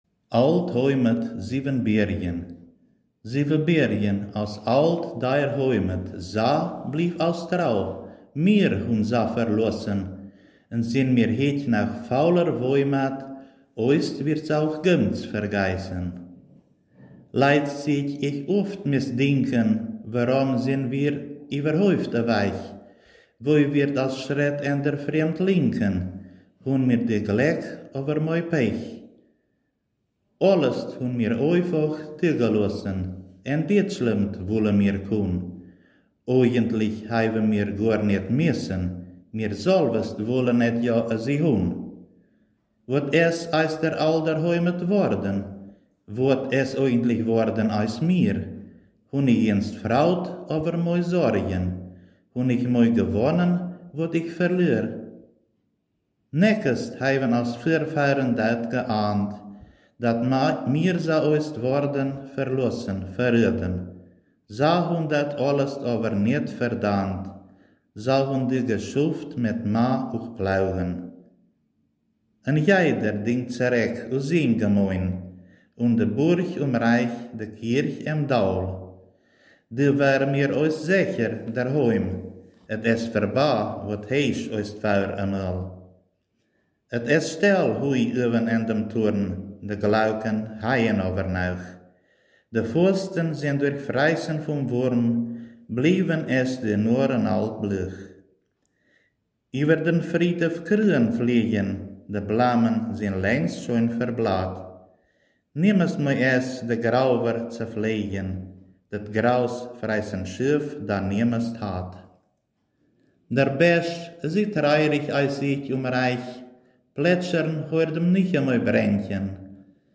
Ortsmundart: Stolzenburg